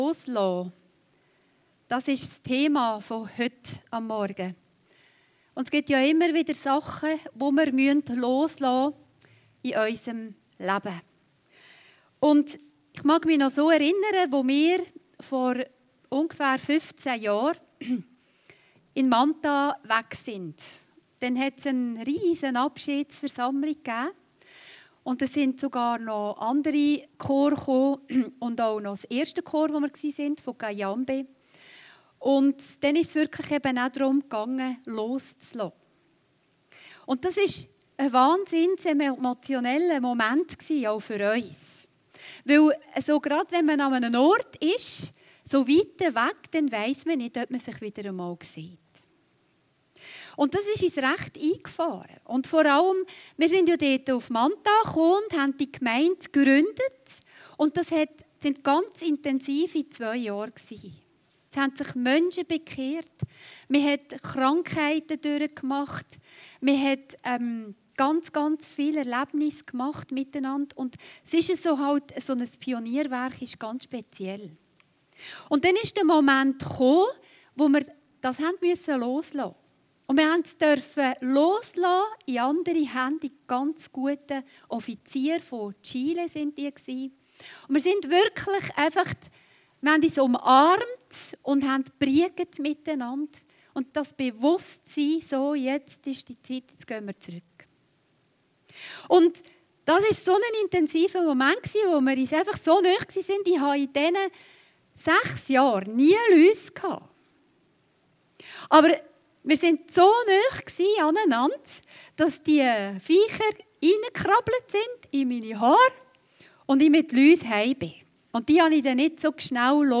Predigten Heilsarmee Aargau Süd – loslassen